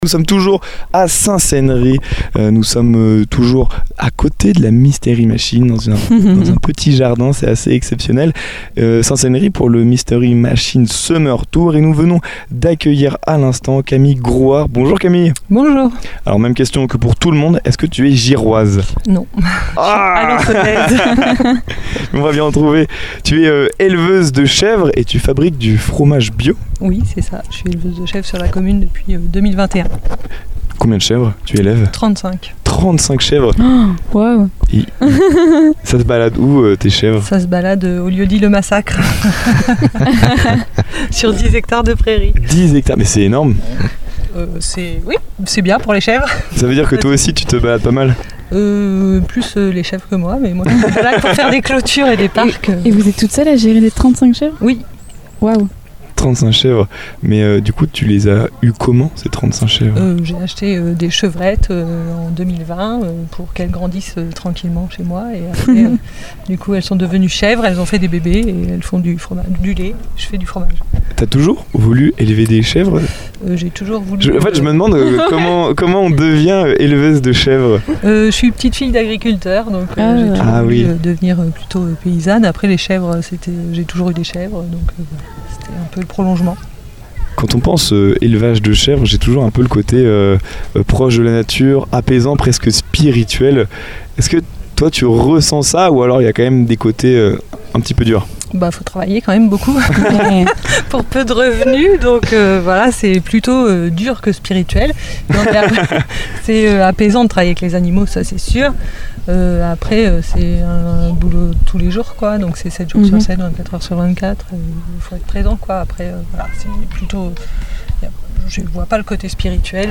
Une interview chaleureuse et sincère qui met en lumière le savoir-faire agricole local et l’énergie de celles et ceux qui font vivre la ruralité normande avec passion.